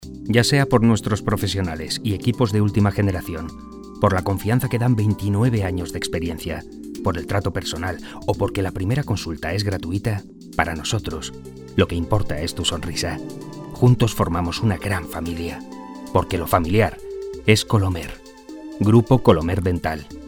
Spanish voice talent. Recording television spot